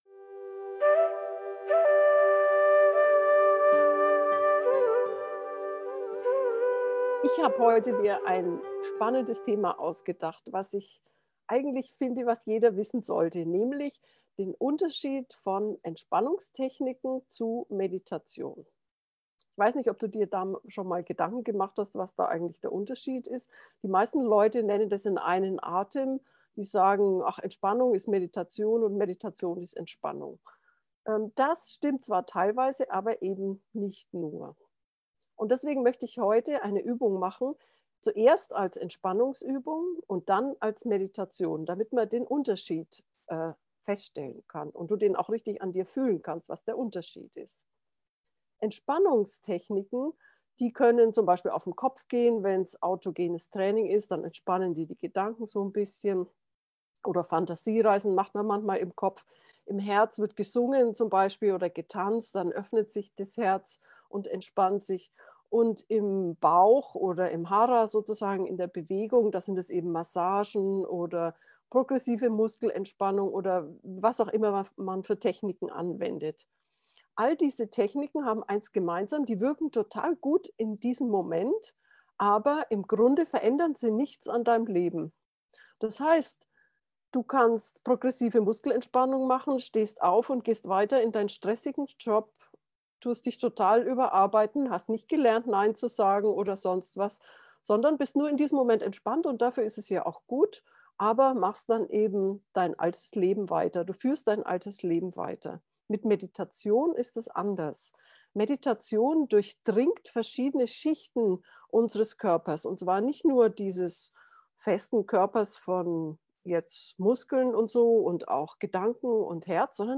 Geführte Meditationen
unterschied-entspannung-meditation-gefuehrte-meditation